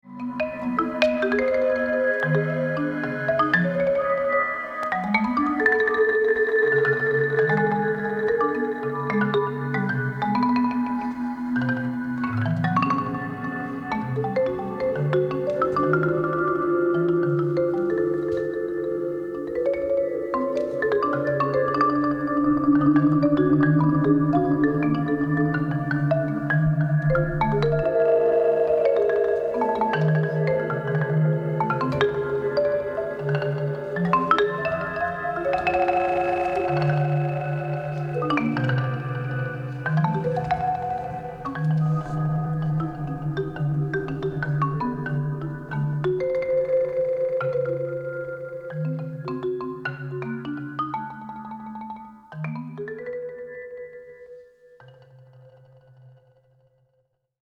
Martin Matalon / Traces IV, pour marimba et électronique